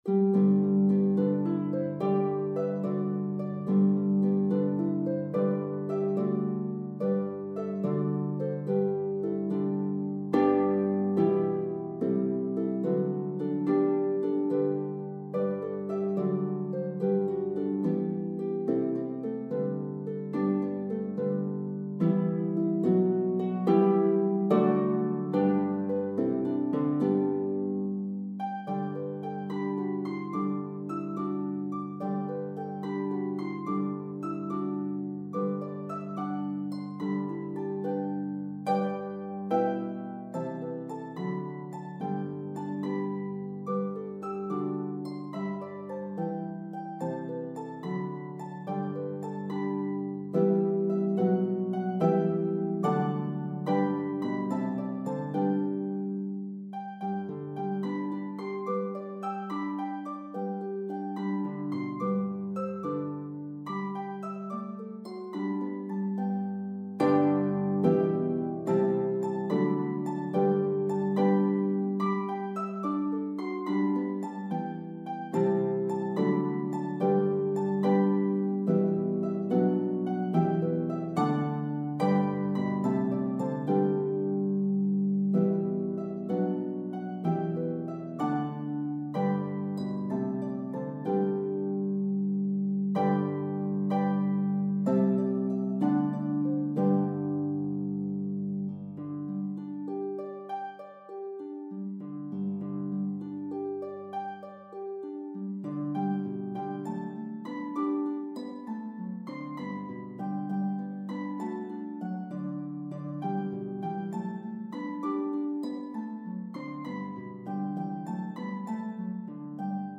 is a toe tapping medley of 3 Christmas favorites.